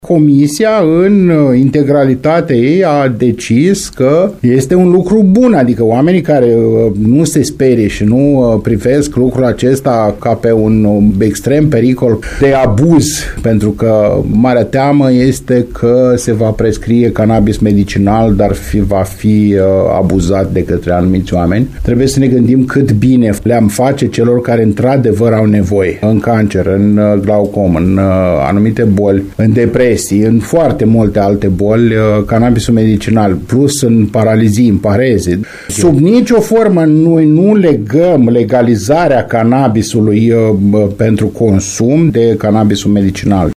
CIORNEI a precizat – în cadrul unei emisiuni la postul nostru – că această lege se aplică în unele state din Uniunea Europeană și a insistat că este vorba despre “folosirea canabisului numai în scopuri terapeutice”.